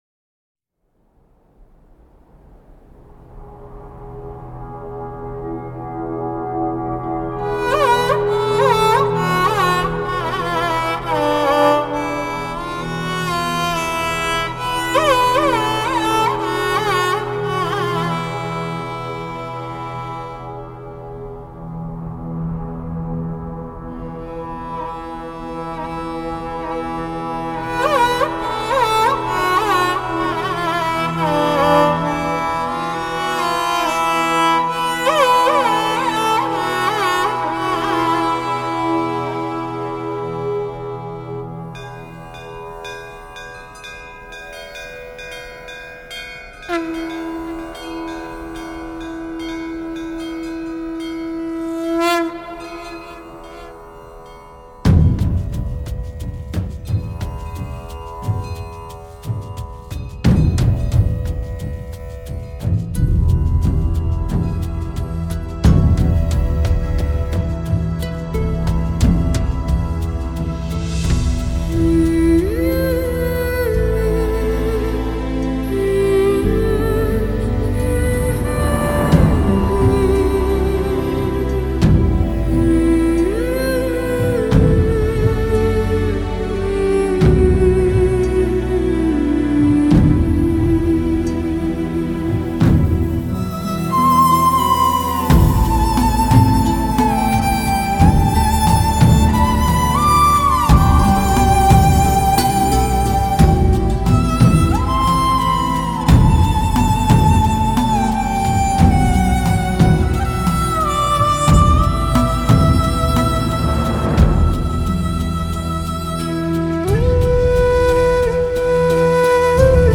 Singer: Instrumental